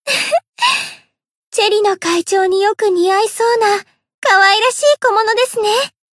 BA_V_Tomoe_Cafe_Monolog_2.ogg